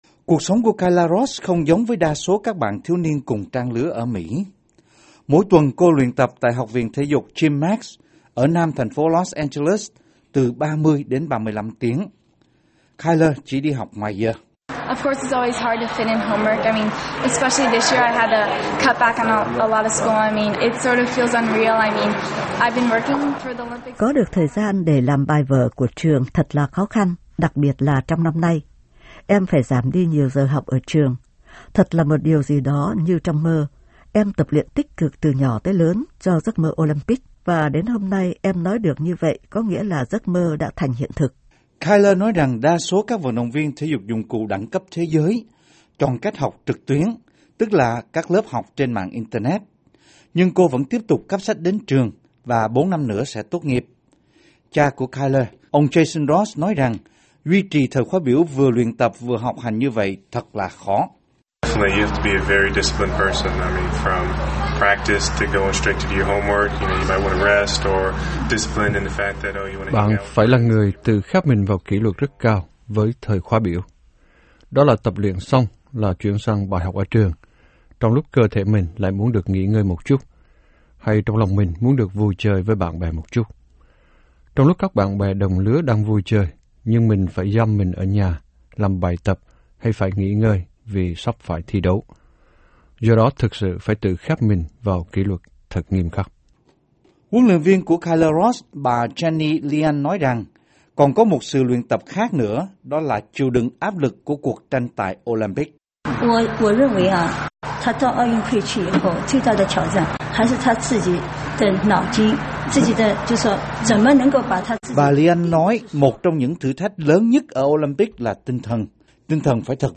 có bài tường trình sau đây